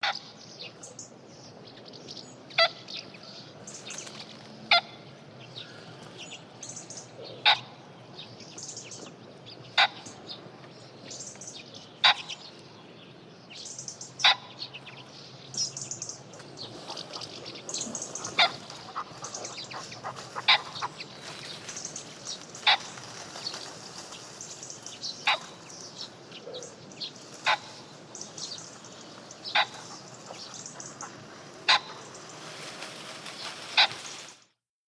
На этой странице собраны разнообразные звуки фазанов – от характерного квохтания до резких тревожных криков.
Самец фазана издает характерные звуки